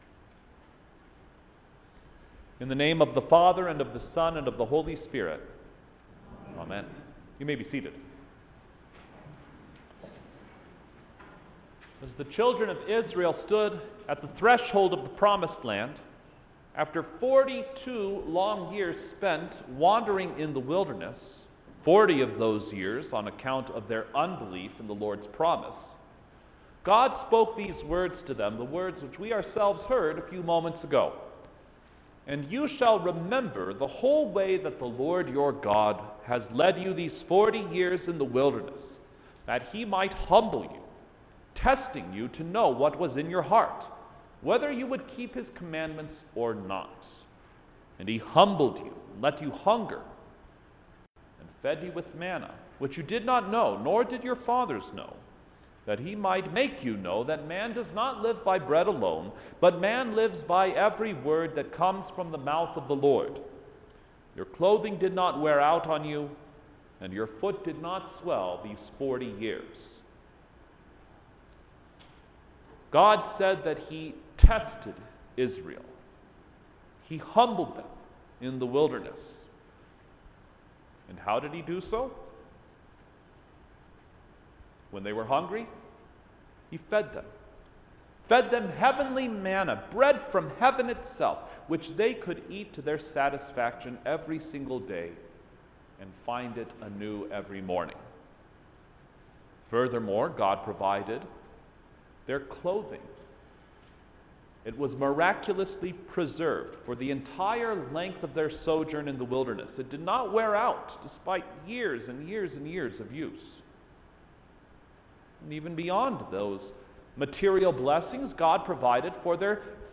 November-25-Thanksgiving-Eve-Prayer-Service_Sermon.mp3